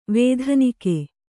♪ vēdhanike